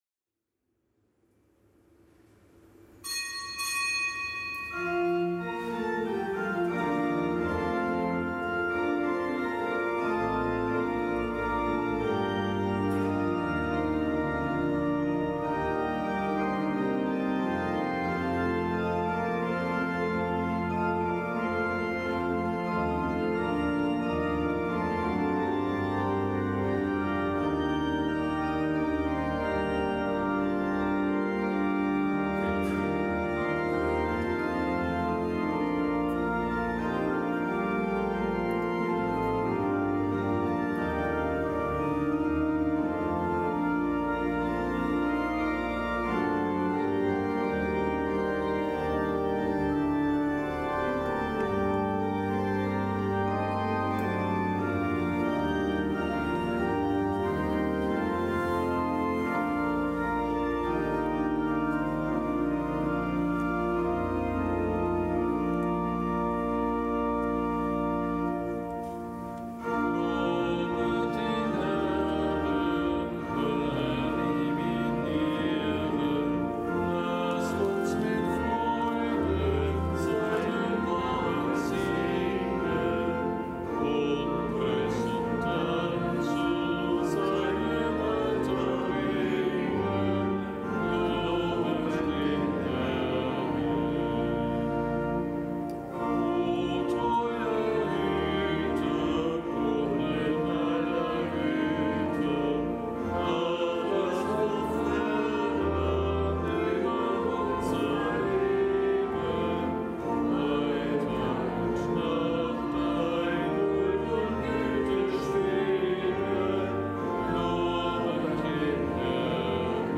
Kapitelsmesse aus dem Kölner Dom am Freitag der vierzehnten Woche im Jahreskreis. Zelebrant: Weihbischof Rolf Steinhäuser.